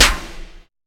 SouthSide Snare Roll Pattern (13).wav